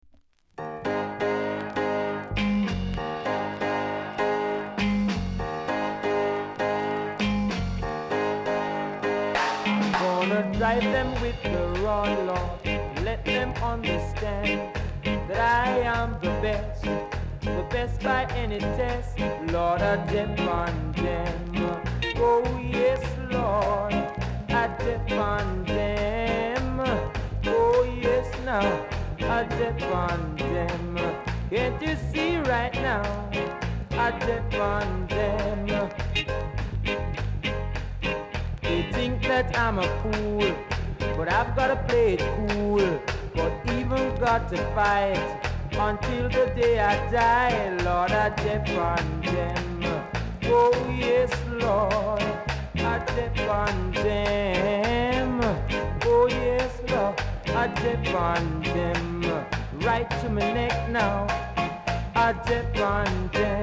※小さなチリ、パチノイズが少しあります。
ROCKSTEADY